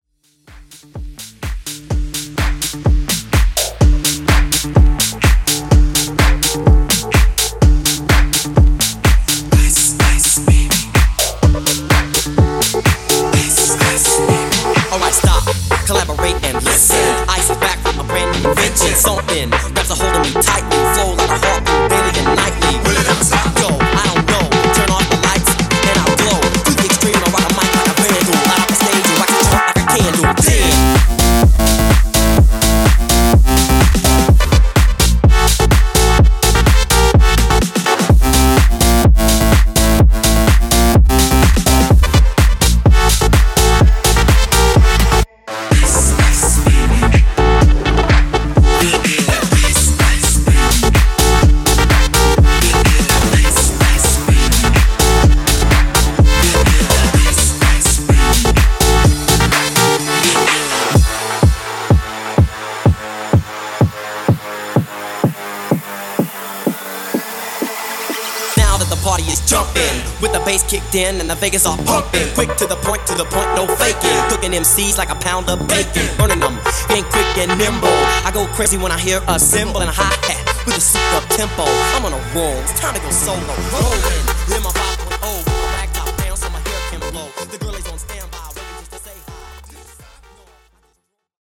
• (Audio & Video Editor) Open Format Dj
Epic Mashup Edit)Date Added